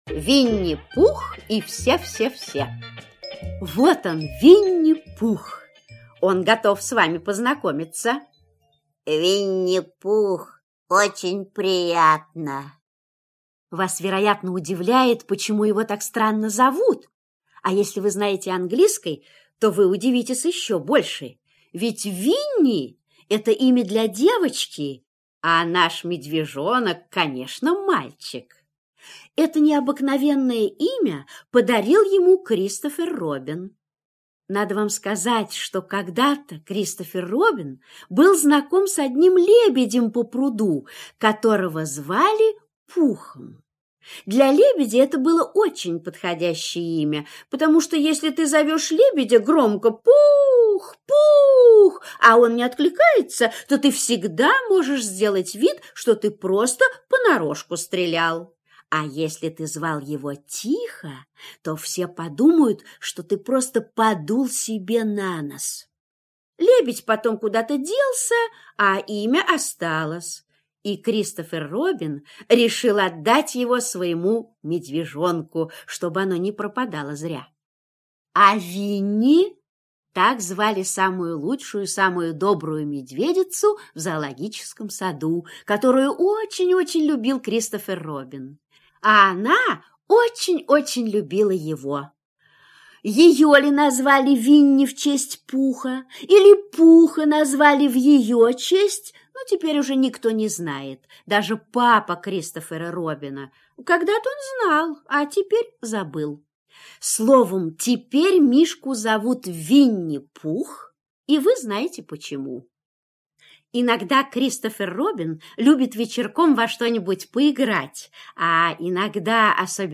Винни-Пух и все-все-все - Милн - слушать сказку онлайн